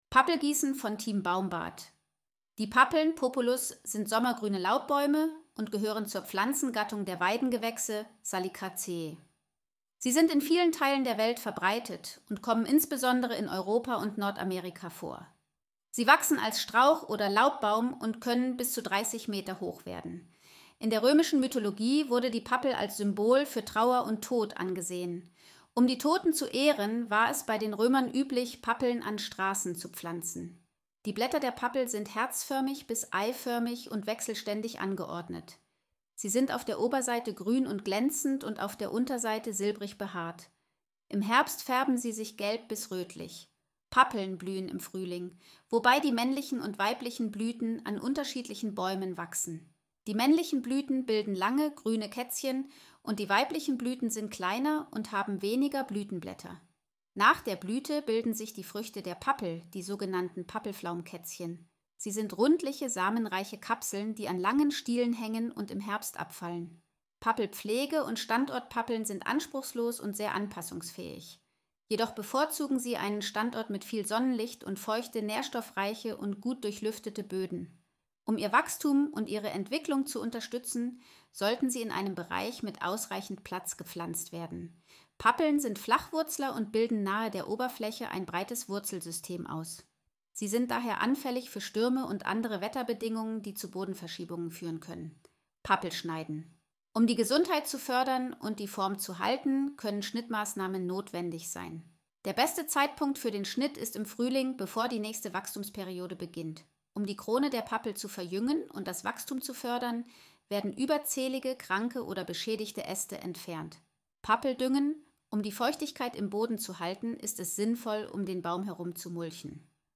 von Team baumbad 17.07.2024 Artikel vorlesen Artikel vorlesen Pappel Herkunft Pappel Pflege und Standort Pappel schneiden Pappel düngen Pappel gießen Pappel Herkunft Die Pappeln (Populus) sind sommergrüne Laubbäume und gehören zur Pflanzengattung der Weidengewächse (Salicaceae).